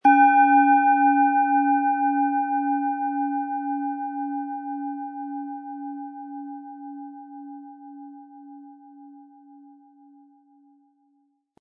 Planetenton 1
Die Schale mit Pluto, ist eine in uralter Tradition von Hand getriebene Planetenklangschale.
MaterialBronze